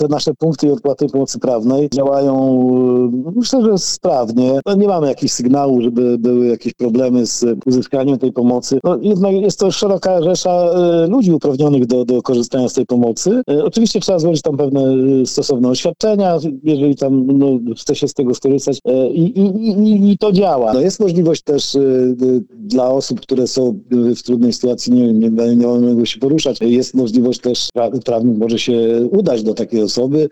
– Porady prawne są finansowane z budżetu Urzędu Wojewódzkiego w Olsztynie, mówi Marek Chojnowski, starosta ełcki.